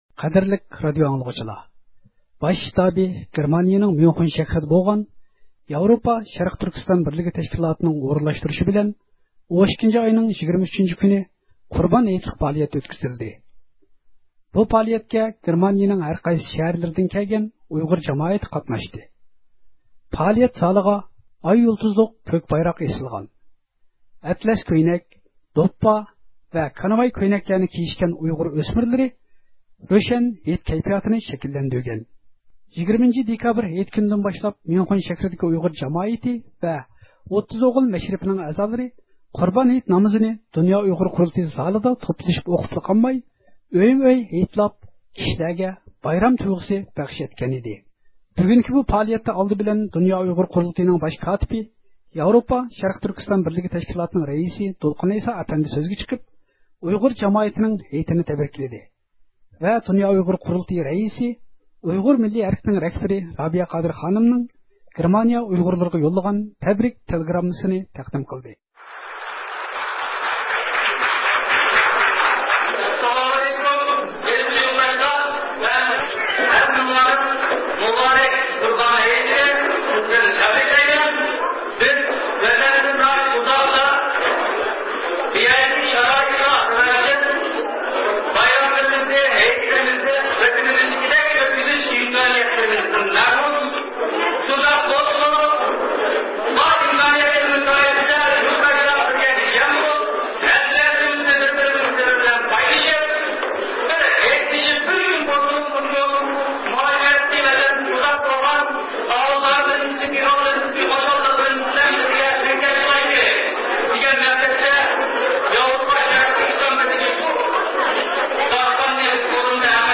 بۇ قېتىم گېرمانىيىدىكى ئۇيغۇر مۇھاجىرلار ميۇنخېن شەھىرىگە يىغىلىپ، قۇربان ھېيتنى شېئىر - دىكلاماتسىيە ئوقۇش، مىللىي ناخشىلارنى ئېيتىش، يۇمۇر ۋە چاقچاقلار بىلەن كۈتۈۋالدى.